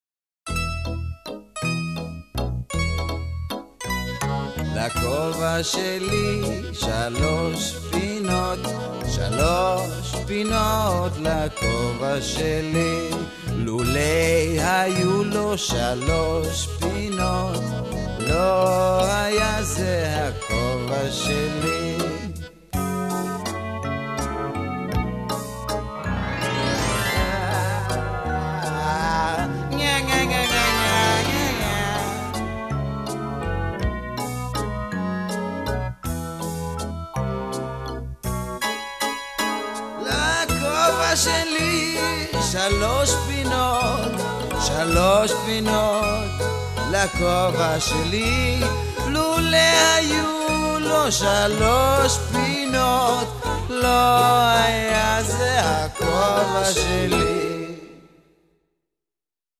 אין מילים!